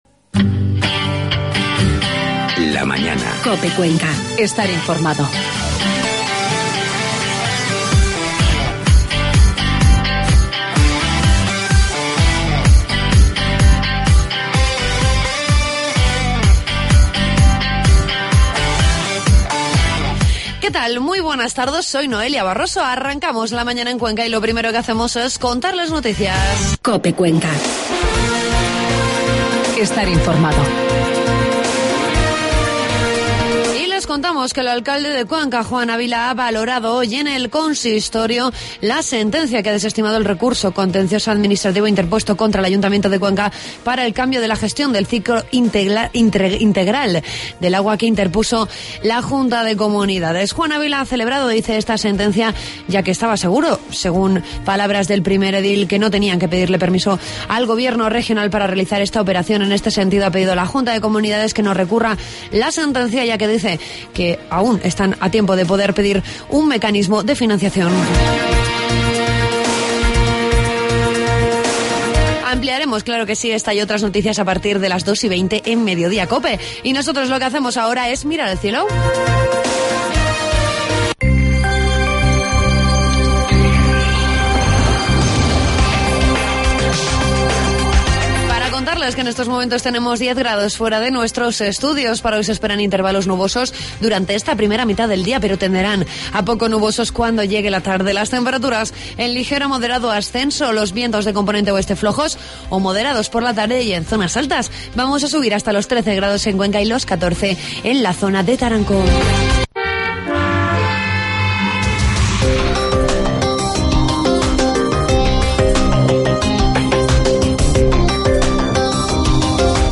Entrevistamos al presidente de la Diputación, Benjamín Prieto con el que tratamos diversos asuntos de la provincia.